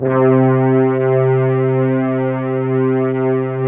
ORCHEST.C3-L.wav